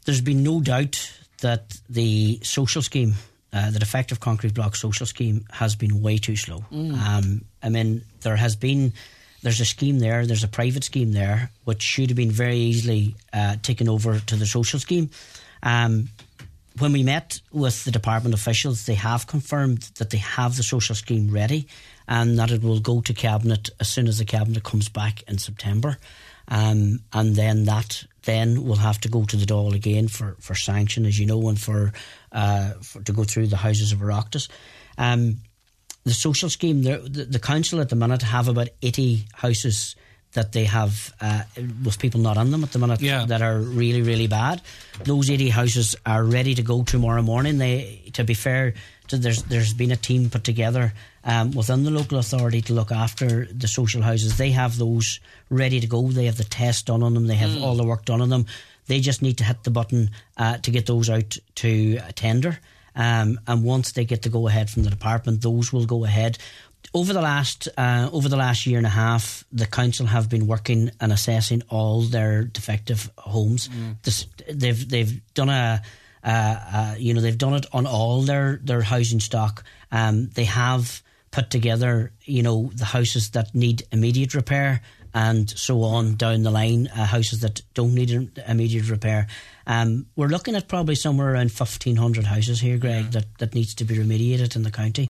Councillor Martin McDermott, Cathaoirleach of the Inishowen Municipal District says once the go-ahead is given by the Department, works will be carried out: